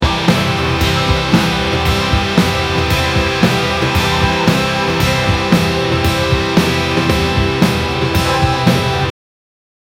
Death Sound Effects - Free AI Generator & Downloads
kicking-the-bucket-uo3lpbpb.wav